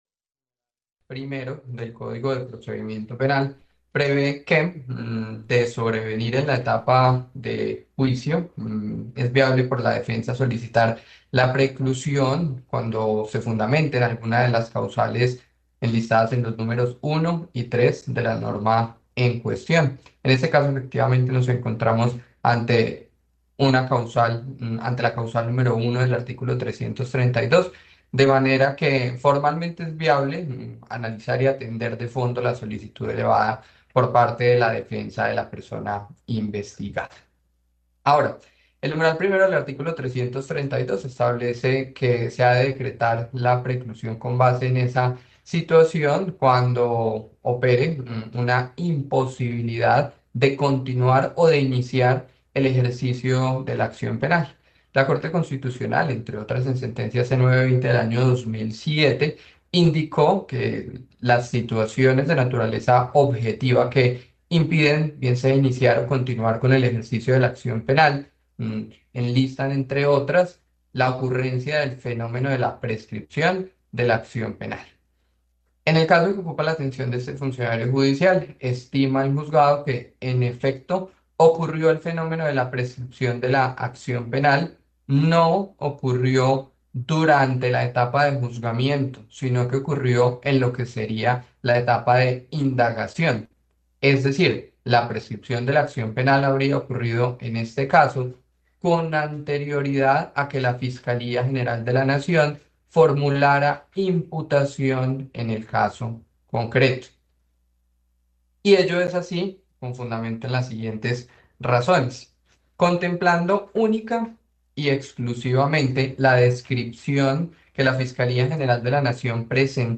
Audiencia en caso del exgobernador, Roberto Jairo Jaramillo